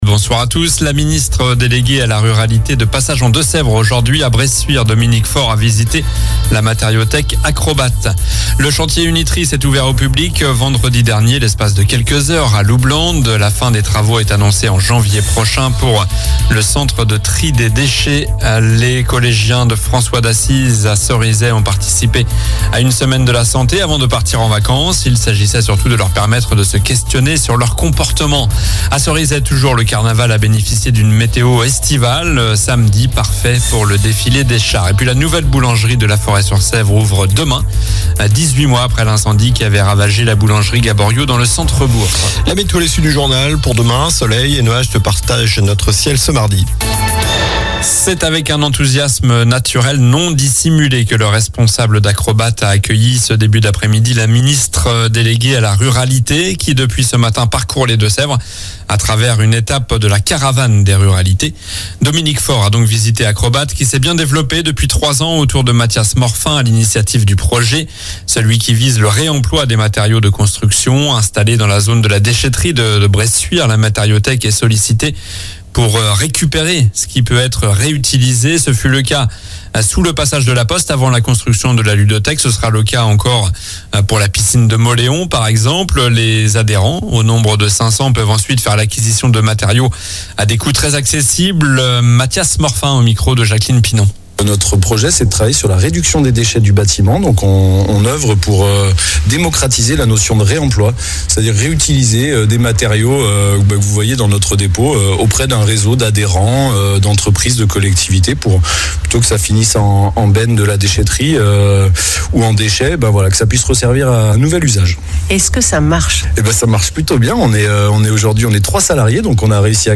Journal du lundi 15 avril (soir)